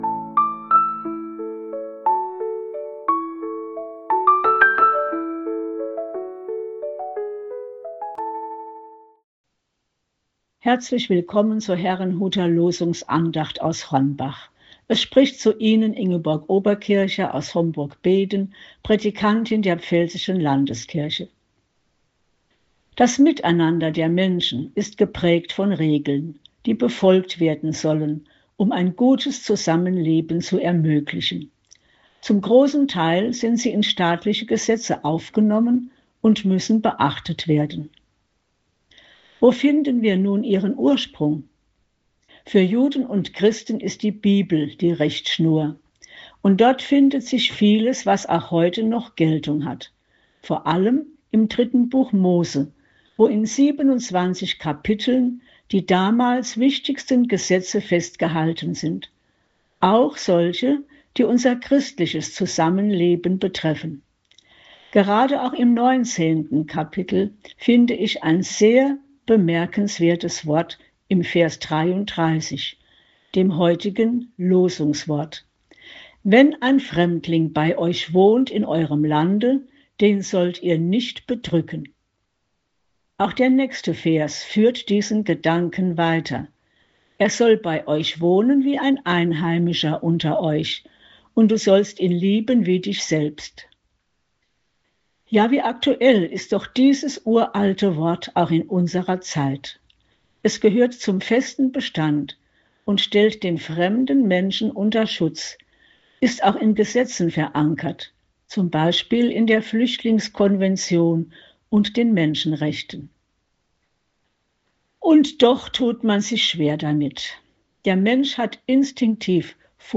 Losungsandacht für Montag, 09.02.2026
Text und Sprecherin: Prädikantin